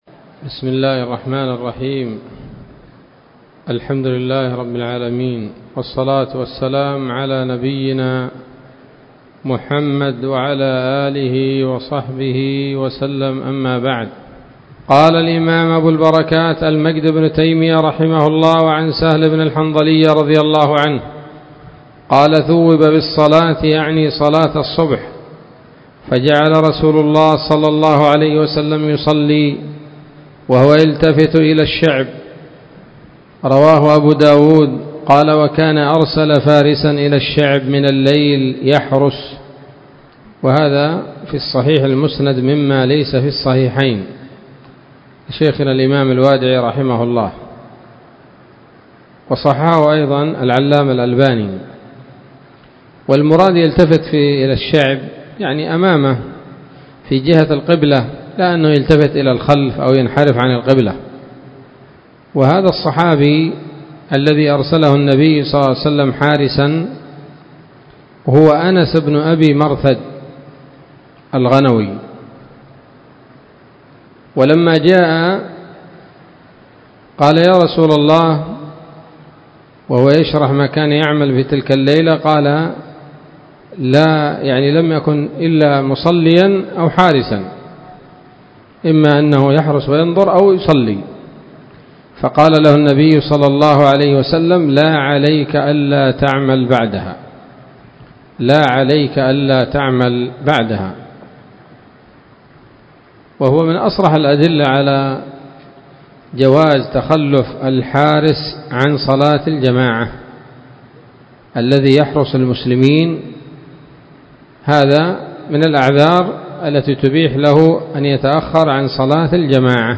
الدرس الثالث عشر من أبواب ما يبطل الصلاة وما يكره ويباح فيها من نيل الأوطار